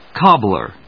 音節cob・bler 発音記号・読み方
/kάblɚ(米国英語), kˈɔblə(英国英語)/